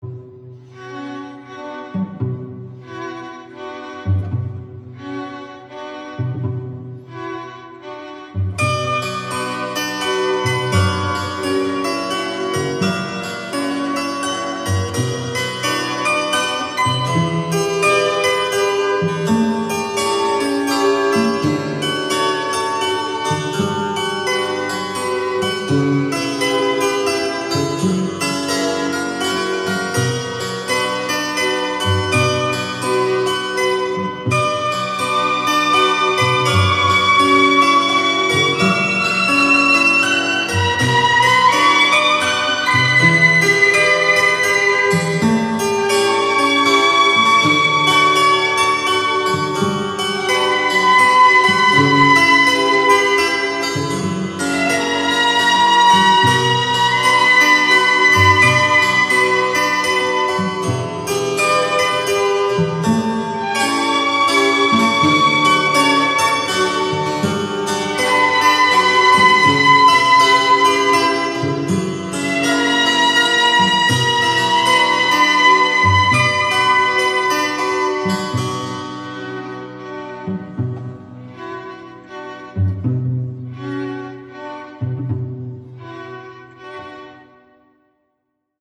Эта скрипочка - просто наслаждение и одновременно головоломка в чарующий вечер пятницы...